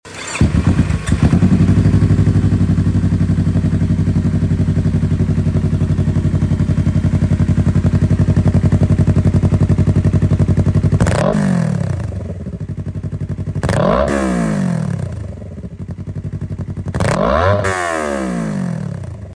Ronco Da YZF R3 2025 Sound Effects Free Download